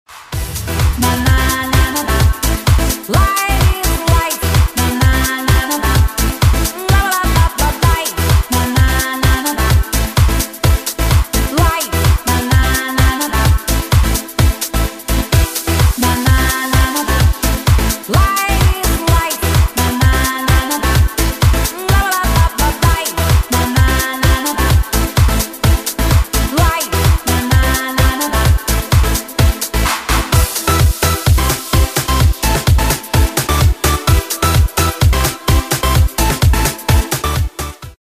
• Качество: 128, Stereo
громкие
женский голос
dance
Electronic
EDM
электронная музыка
Euro House